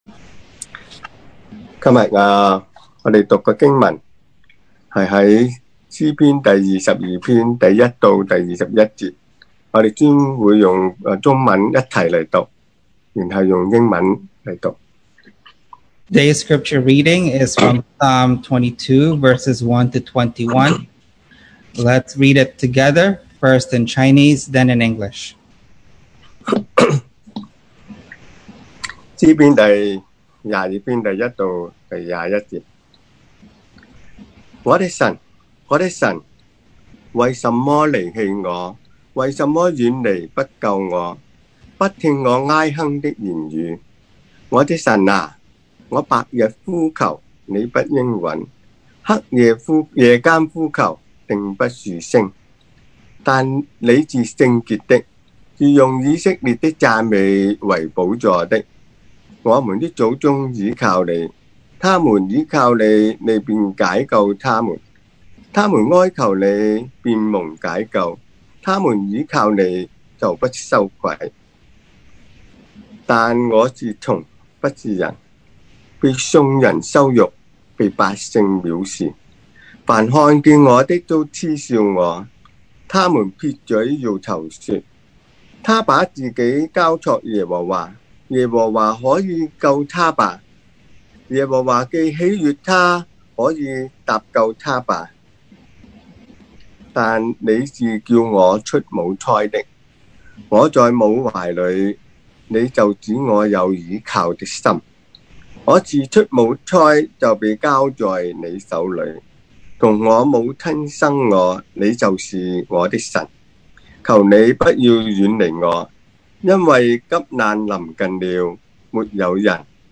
Passage: Psalm 22:1-21 Service Type: Sunday Morning